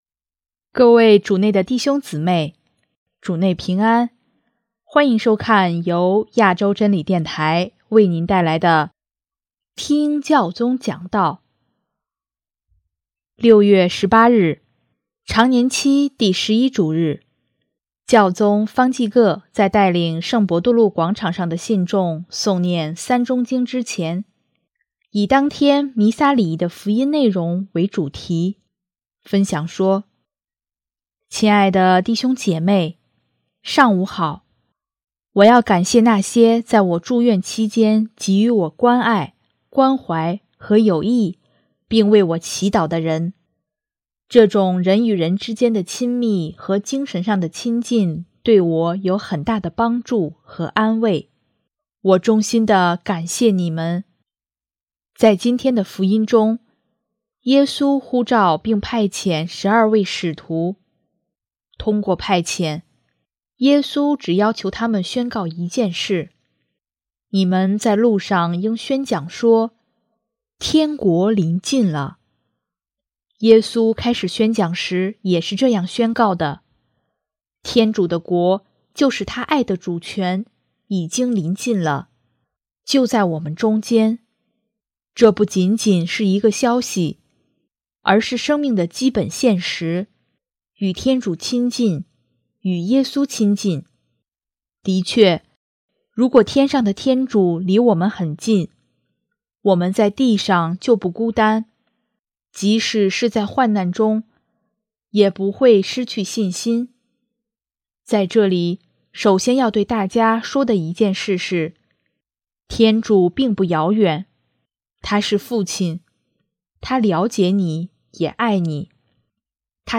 首页 / 听教宗讲道/ 新闻/ 教宗方济各